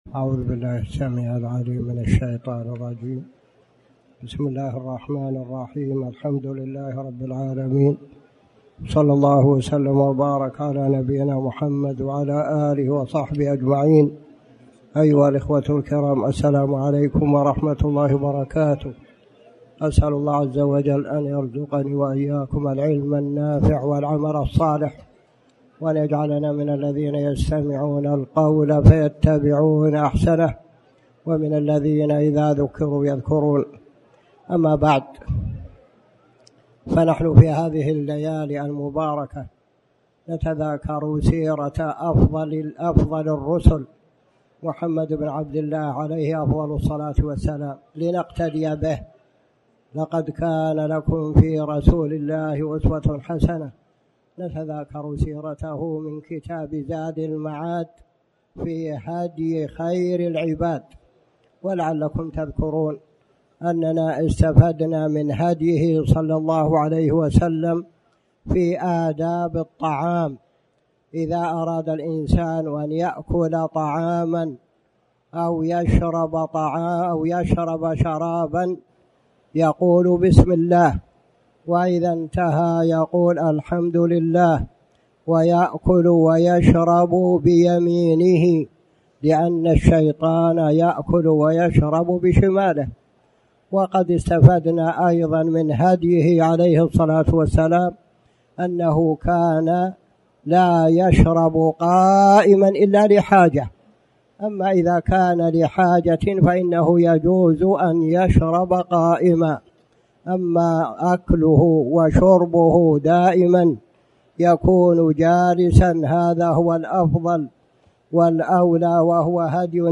تاريخ النشر ١٤ رجب ١٤٣٩ هـ المكان: المسجد الحرام الشيخ